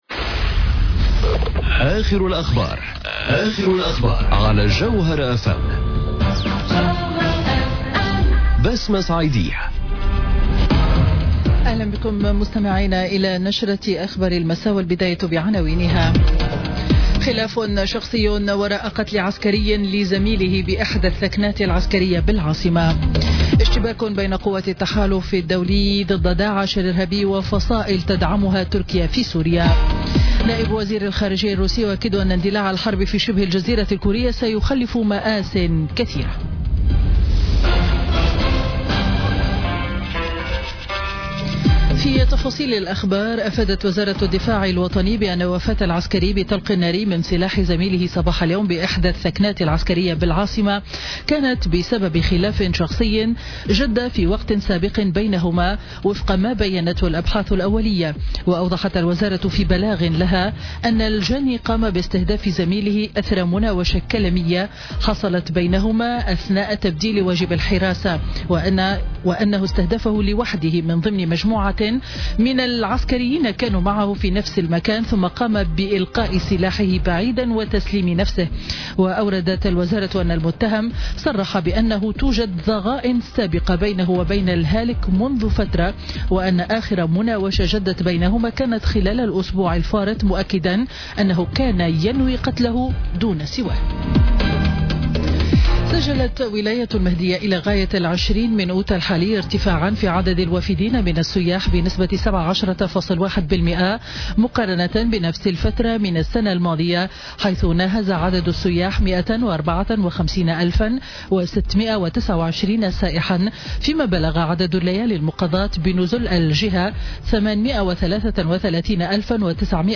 نشرة أخبار السابعة مساء ليوم الثلاثاء 30 أوت 2017